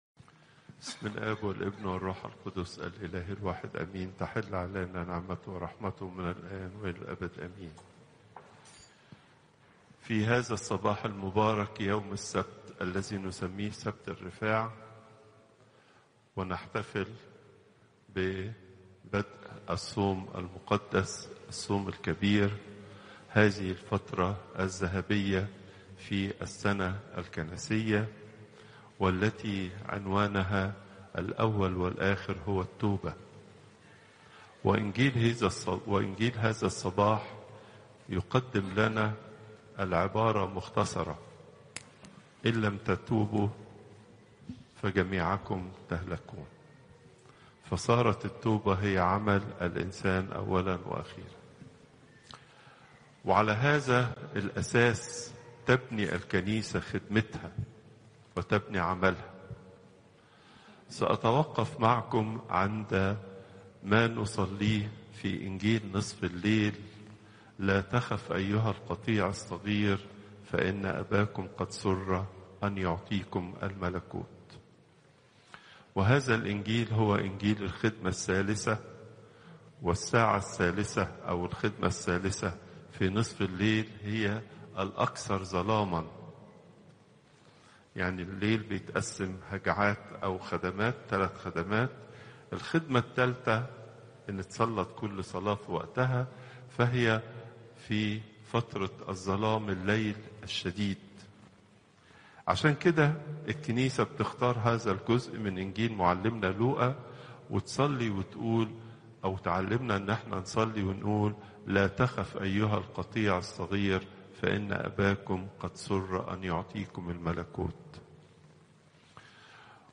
Popup Player تحميل الصوت البابا تواضروس الثانى السبت، 22 فبراير 2025 21:02 المحاضرة الأسبوعية لقداسة البابا تواضروس الثاني الزيارات: 365